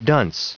Prononciation du mot dunce en anglais (fichier audio)
Prononciation du mot : dunce